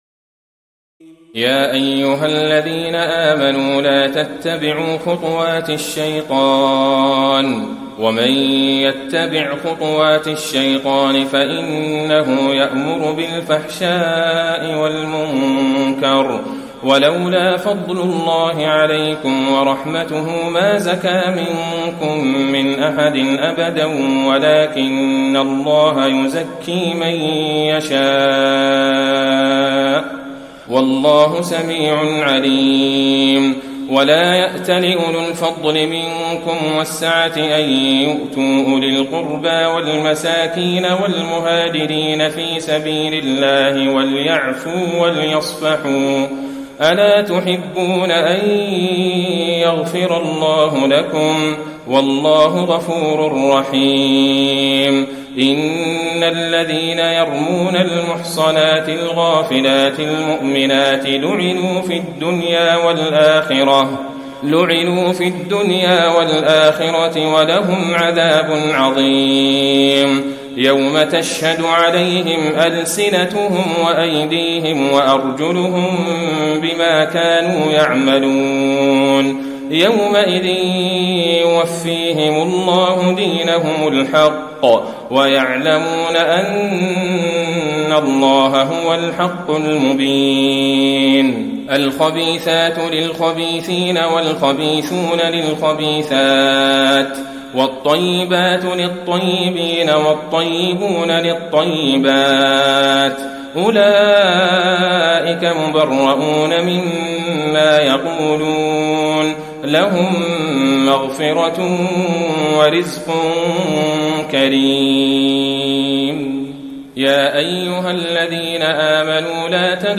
تراويح الليلة السابعة عشر رمضان 1435هـ من سورتي النور (21-64) و الفرقان (1-20) Taraweeh 17 st night Ramadan 1435H from Surah An-Noor and Al-Furqaan > تراويح الحرم النبوي عام 1435 🕌 > التراويح - تلاوات الحرمين